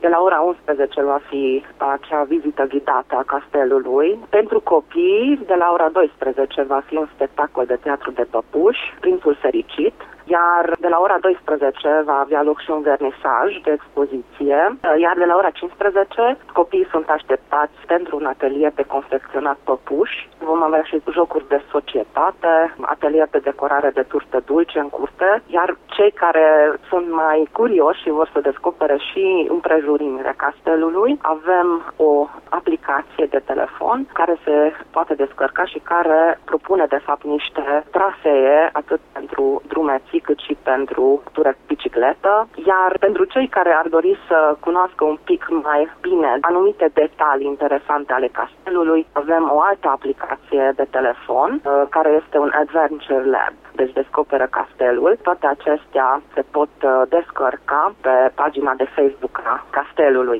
istoric de artă: